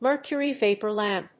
アクセントmércury‐vápor làmp